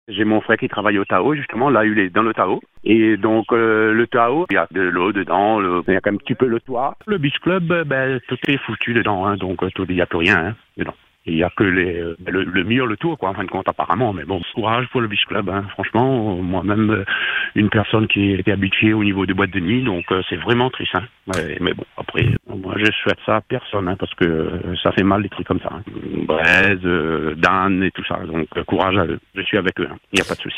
habitué des nuits de Saint-Gilles, témoigne sur notre antenne.